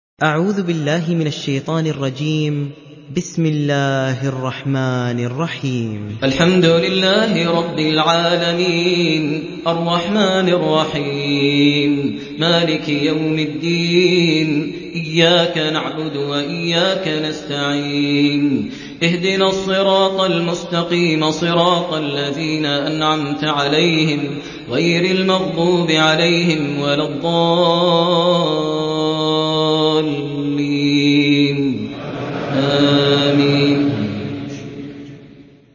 1111 > mushf > Mushaf - Maher Almuaiqly Recitations